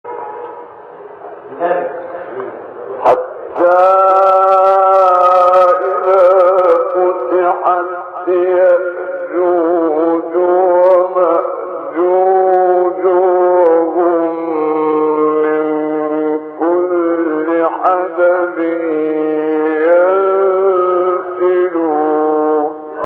گروه فعالیت‌های قرآنی: فرازهایی در مقام صبا با صوت محمد عمران ارائه می‌شود.
برچسب ها: خبرگزاری قرآن ، ایکنا ، فعالیت های قرآنی ، مقام صبا ، محمد عمران ، قاری مصری ، فراز صوتی ، نغمه ، قرآن ، iqna